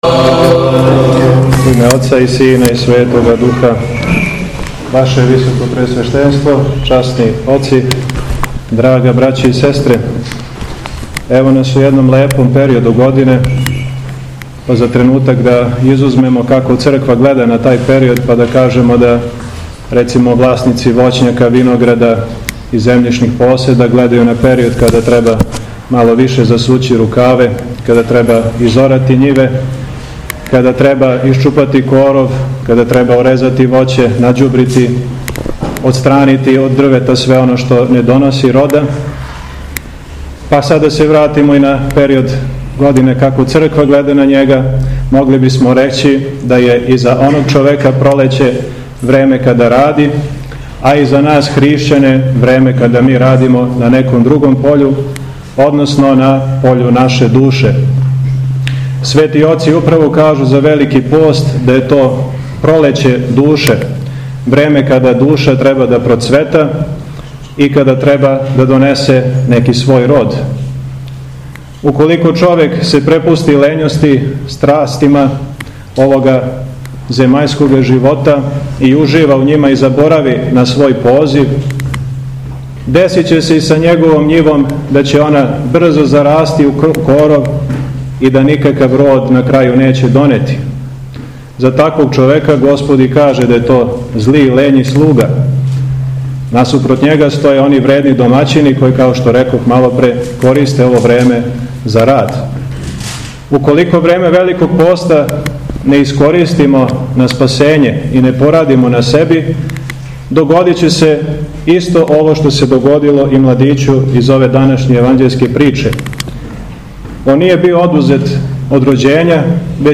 СВЕТА АРХИЈЕРЕЈСКА ЛИТУРГИЈА У ХРАМУ СВЕТОГ ВЕЛИКОМУЧЕНИКА ДИМИТРИЈА У СУШИЦИ - Епархија Шумадијска